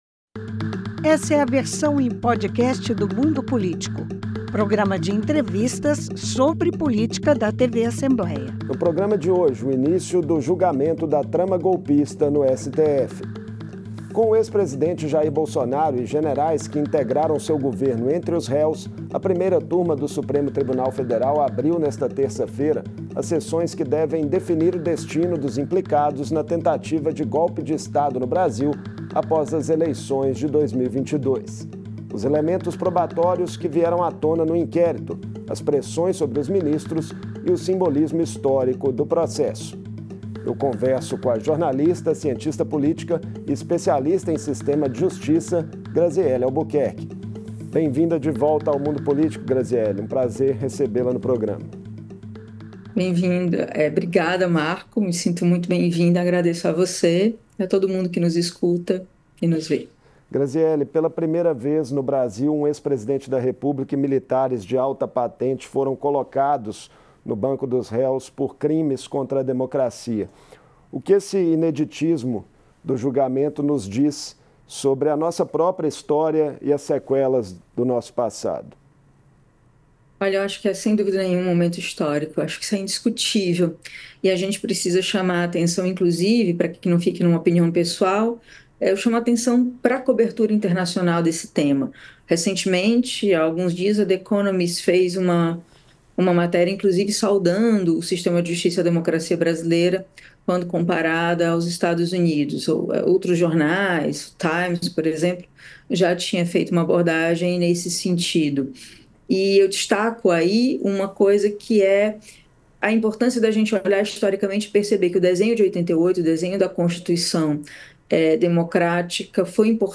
O ex-presidentre Jair Bolsonaro e outros sete réus do núcleo crucial da tentativa de golpe de Estado começaram a ser julgados pelo Supremo Tribuna Federal. Em entrevista